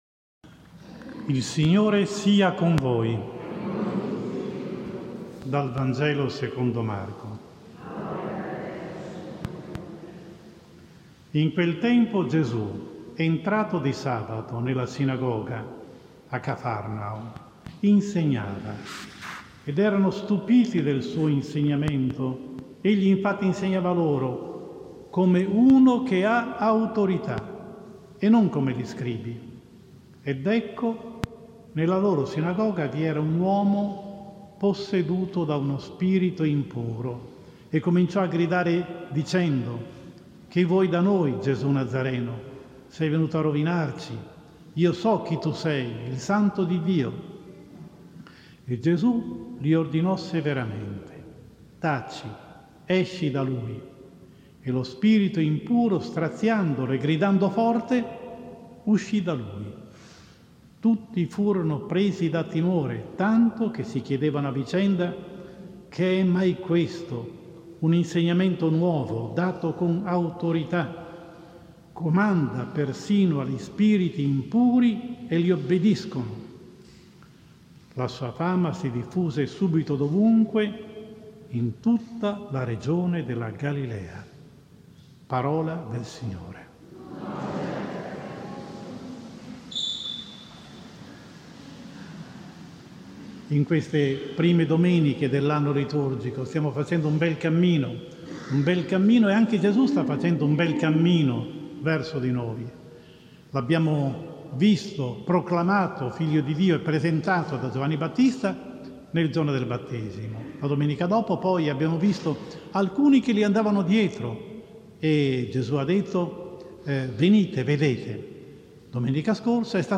31 Gennaio 2021: IV Domenica del tempo ordinario (Anno B) – omelia